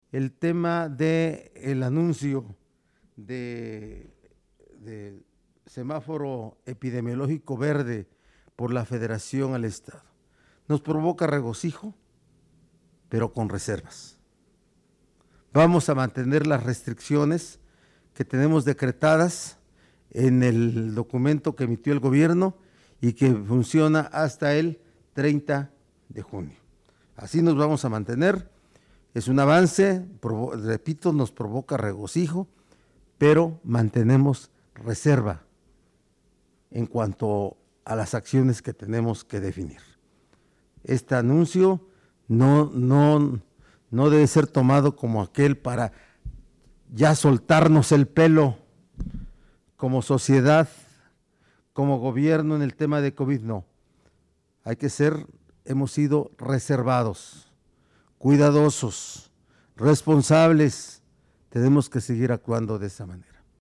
Así lo sostuvo el gobernador Miguel Barbosa Huerta en la habitual videoconferencia de prensa, en la que puntualizó que este descenso no significa que la sociedad deba bajar la guardia ante la pandemia por COVID-19, motivo por el cual llamó a las y los poblanos a seguir con las medidas sanitarias: sana distancia, uso de gel antibacterial y cubrebocas.